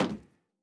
added stepping sounds
LowMetal_Mono_02.wav